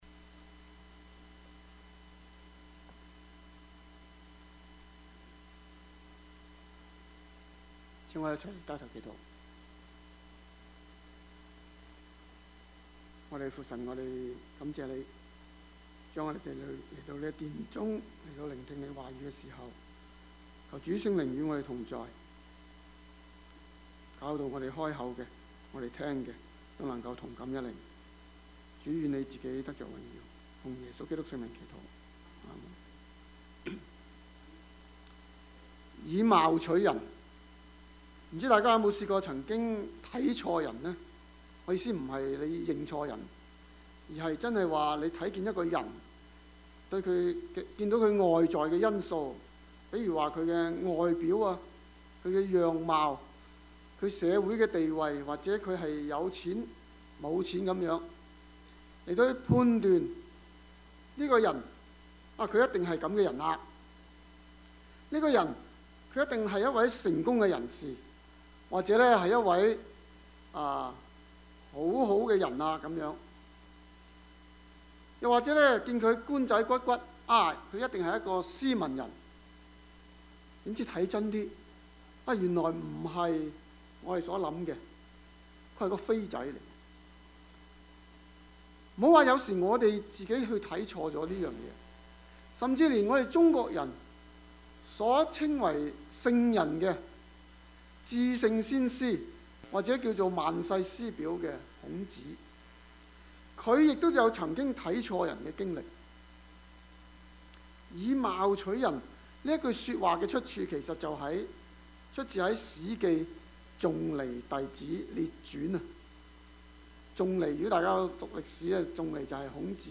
Service Type: 主日崇拜
Topics: 主日證道 « 復活 – 真有其事?